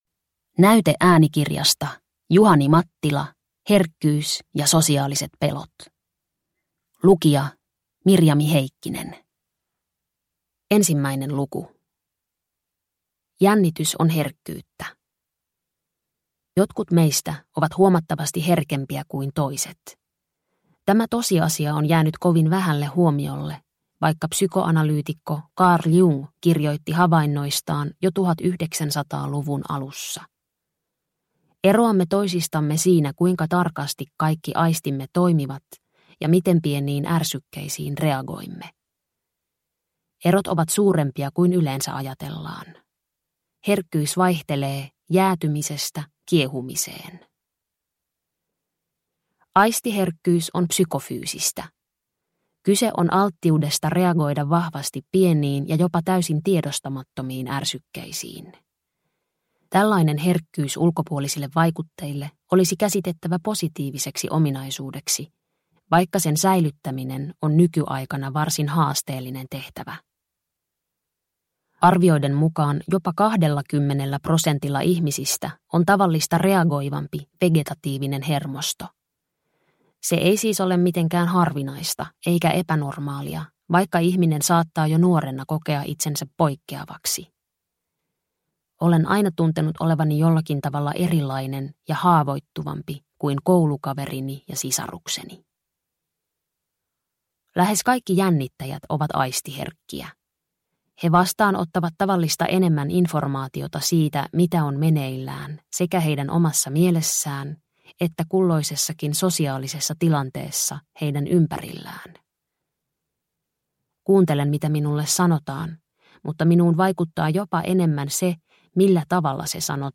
Herkkyys ja sosiaaliset pelot – Ljudbok – Laddas ner